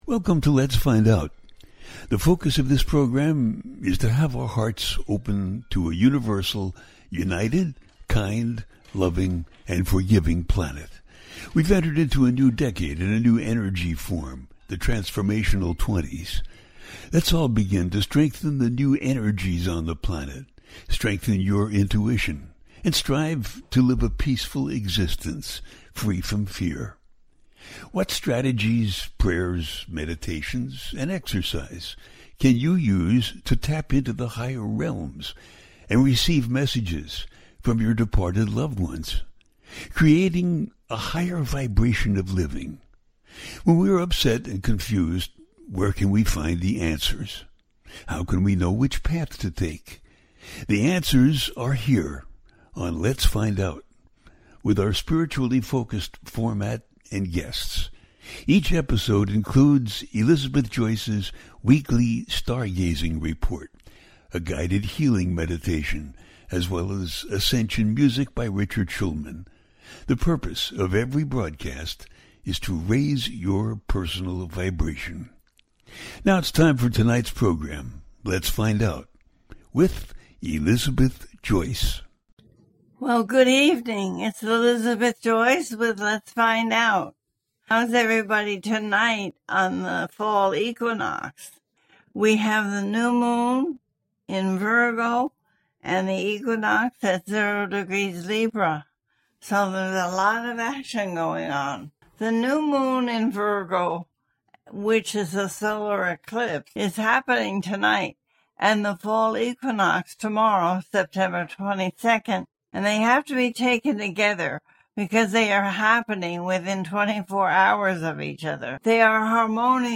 Libra Brings A New Moon Eclipse And Fall Equinox - A teaching show
The listener can call in to ask a question on the air.
Each show ends with a guided meditation.